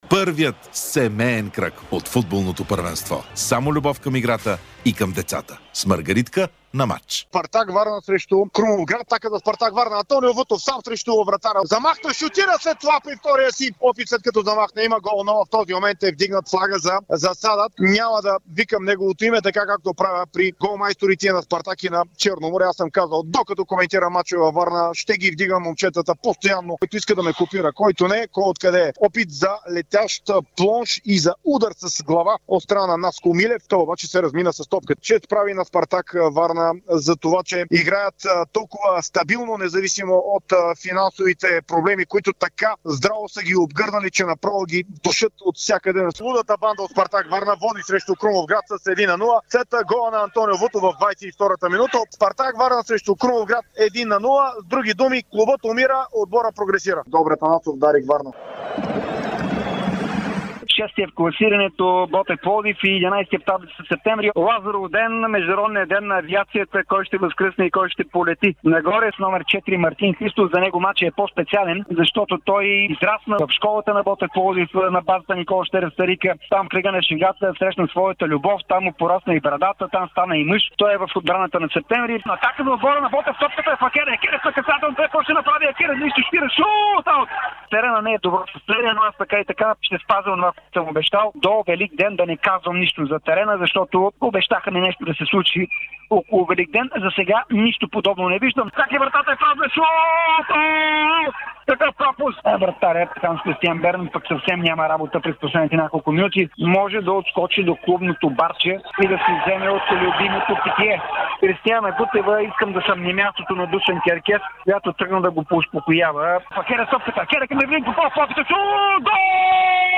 Какво чухме в ефира на Дарик радио в 29 кръг на efbet Лига - Левски допусна обрат от Черно море у дома, Ботев Пловдив най-после победи през 2025 година, а Лудогорец се измъчи при гостуването на последния. ЦСКА спечели, но не успя да влезе в топ 4!